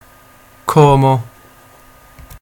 Como (Italian: [ˈkɔːmo] ,[3][4] locally [ˈkoːmo]
It-Como(local).ogg.mp3